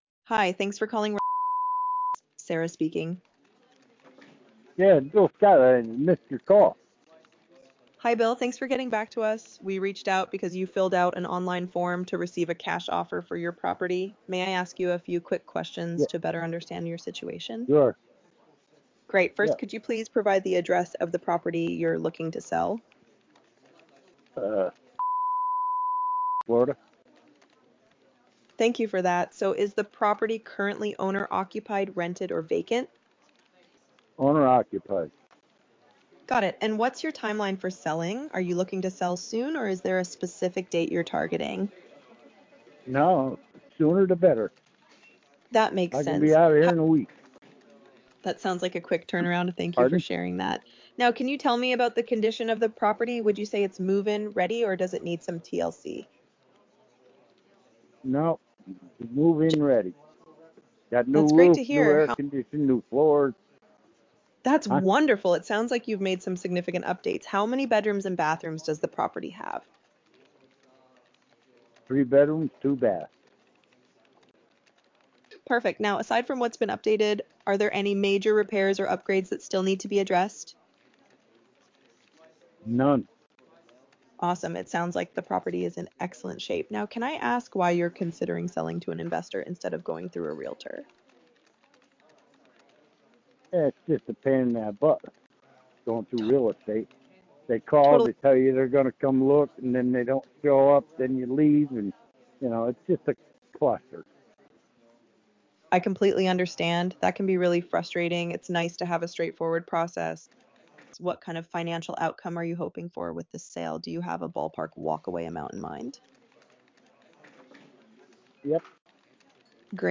Spanish and English AI agents ready to help your business grow
Sample Real Estate Call